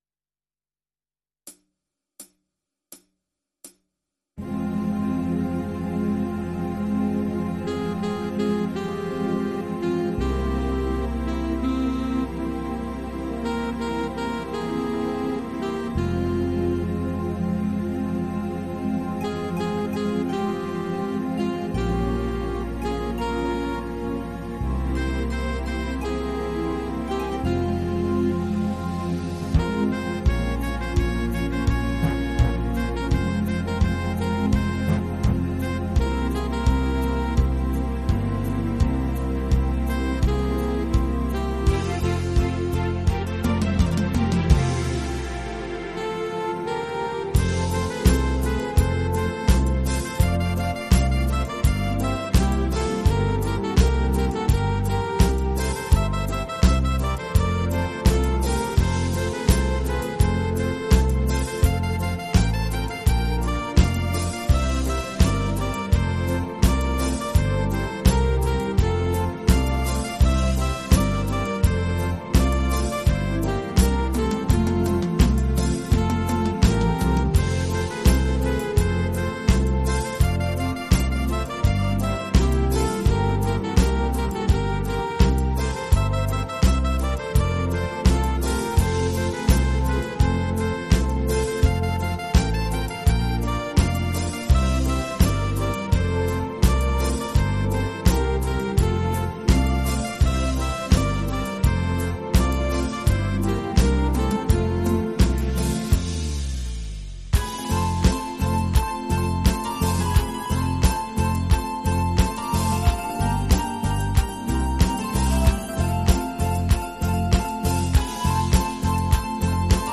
MIDI Karaoke version
PRO MIDI INSTRUMENTAL VERSION